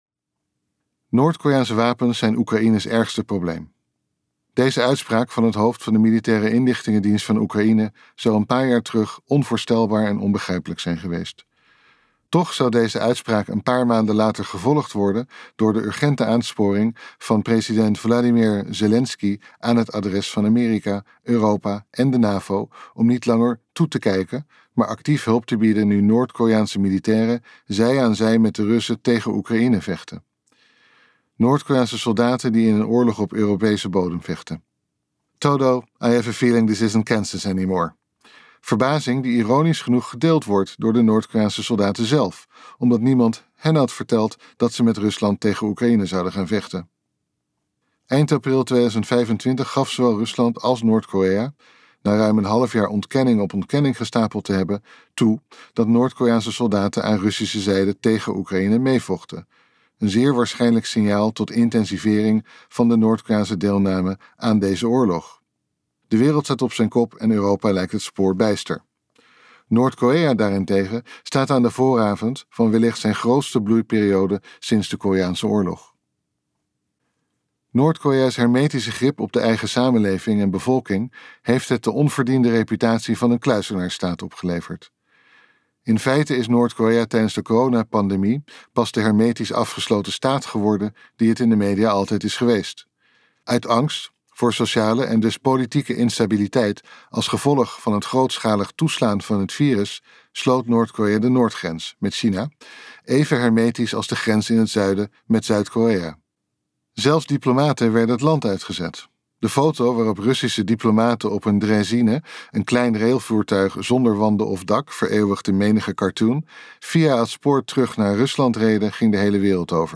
Ambo|Anthos uitgevers - De wereld volgens noord korea luisterboek